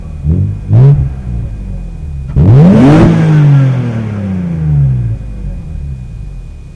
exhaust[1].wav